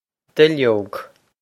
duilleog dill-ohg
Pronunciation for how to say
This is an approximate phonetic pronunciation of the phrase.